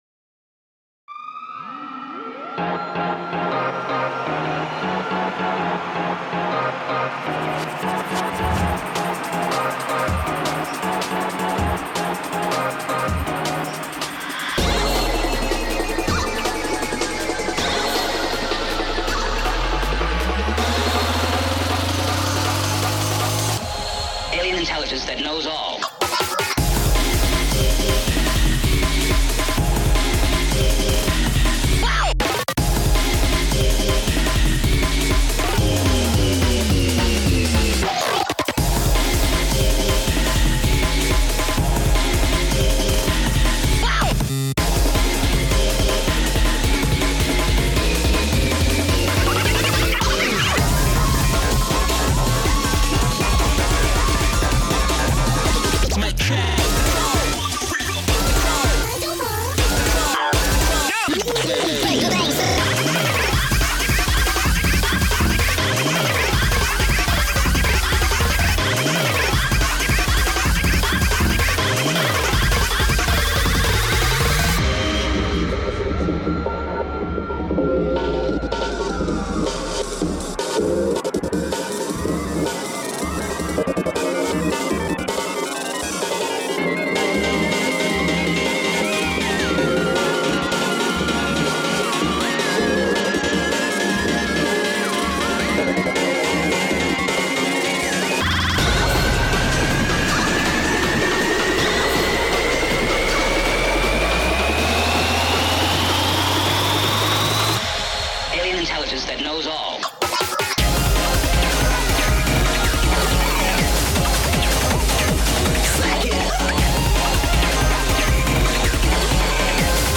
BPM80-160
Audio QualityPerfect (Low Quality)